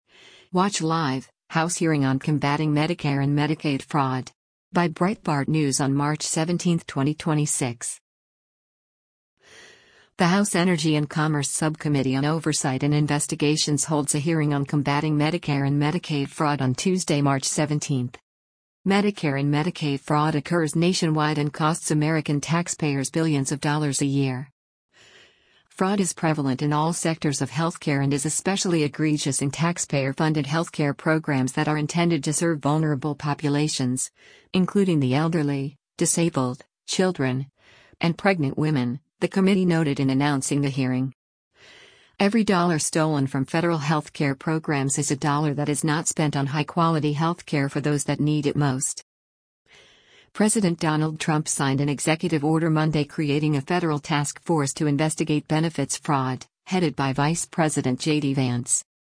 The House Energy and Commerce Subcommittee on Oversight and Investigations holds a hearing on combatting Medicare and Medicaid fraud on Tuesday, March 17.